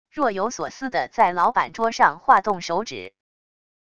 若有所思地在老板桌上划动手指wav音频